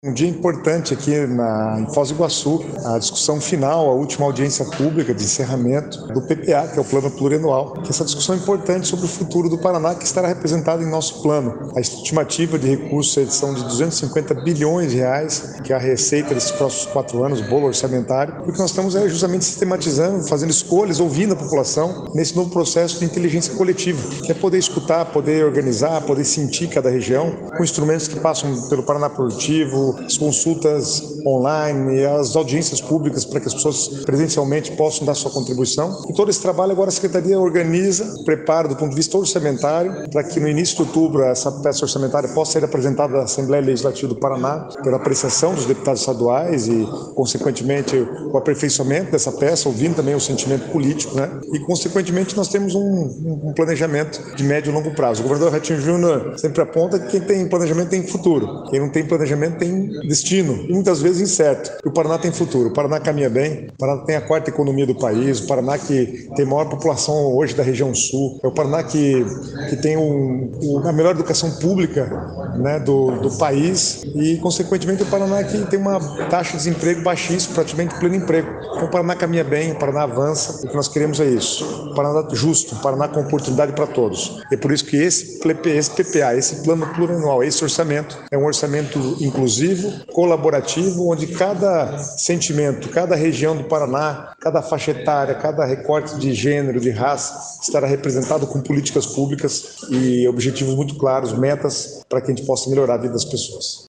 Sonora do secretário do Planejamento, Guto Silva, sobre a apresentação do Plano Plurianual de R$ 250 bilhões em Foz do Iguaçu
GUTO SILVA - AUDIENCIA PUBLICA PPA FOZ.mp3